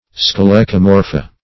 Search Result for " scolecomorpha" : The Collaborative International Dictionary of English v.0.48: Scolecomorpha \Sco*le`co*mor"pha\, n. pl.